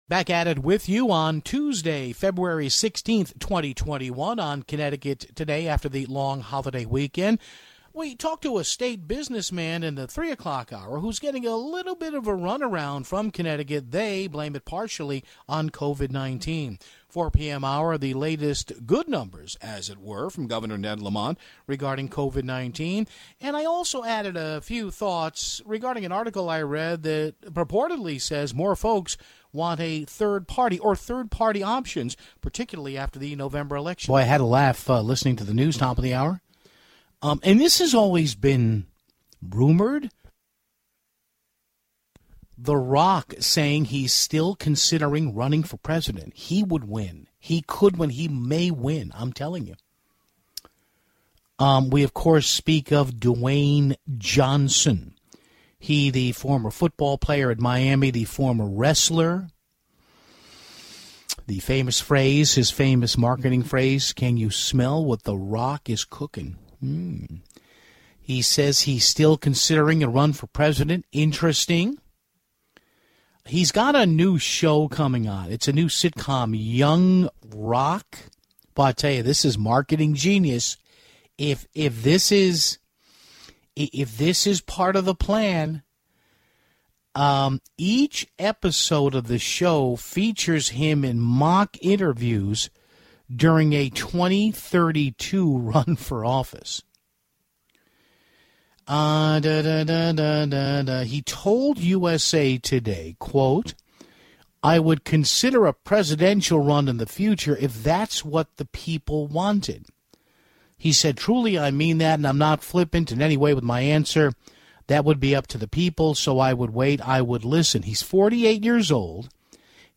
Finally, we carried Governor Ned Lamont’s first pandemic media update for the week (21:08).